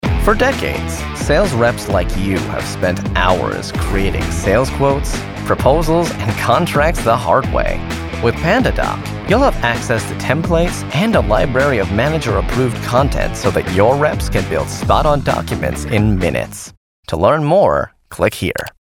Inglés (Americano)
Natural, Amable, Cálida
Corporativo